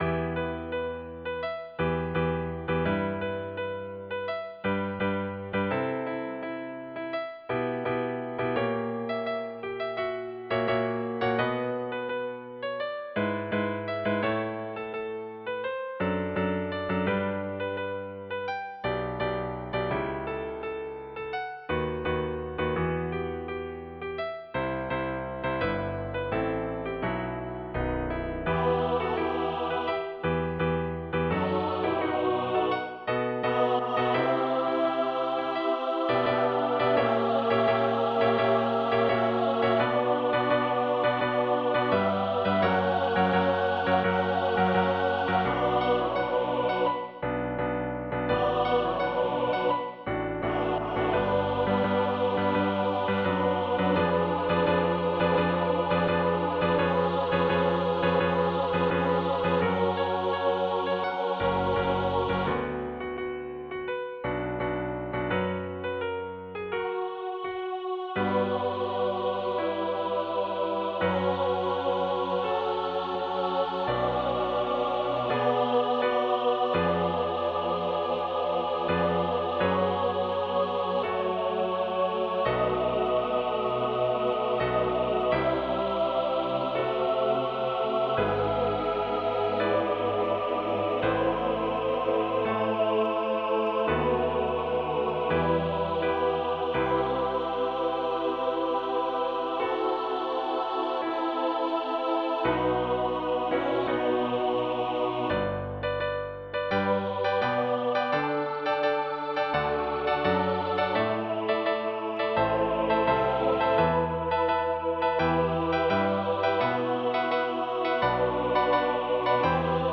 Easter Anthem
in a thematic and harmonic four minute medley